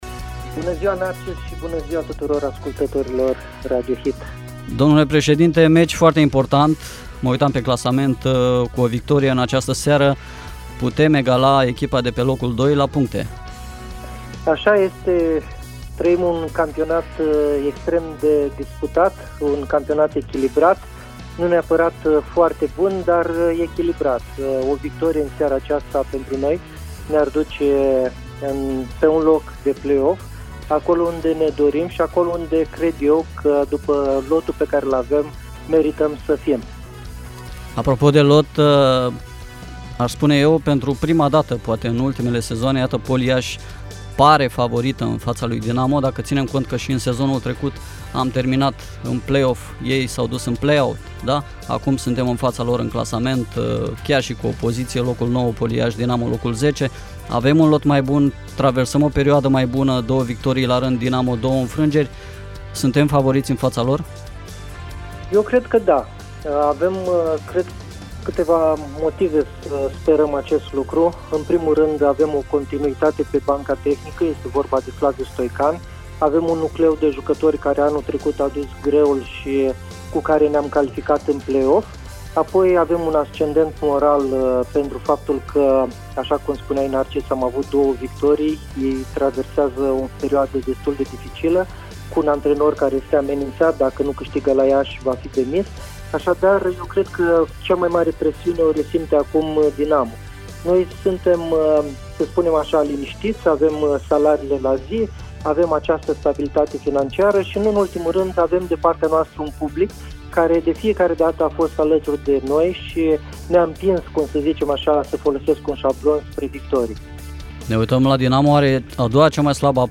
a prefațat partida cu Dinamo în direct la Radio HIT